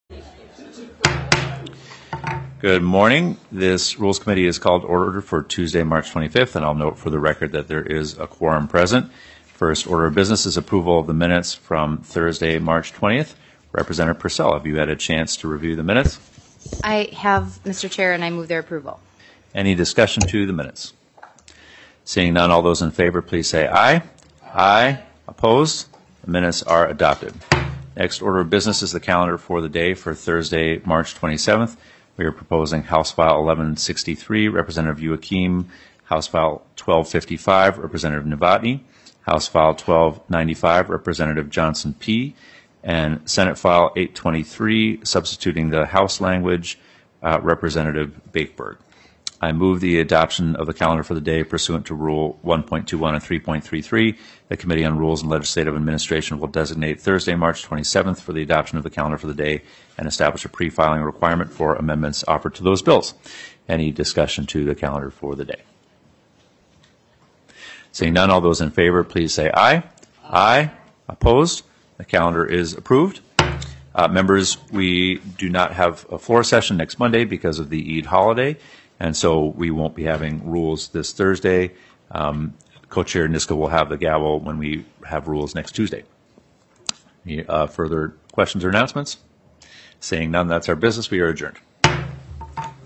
Rules and Legislative Administration Committee Minutes
Representative Jamie Long, Co-Chair of the Committee on Rules and Legislative Administration, called the meeting to order at 10:02 am on Tuesday, March 25, 2025 in Capitol room G3.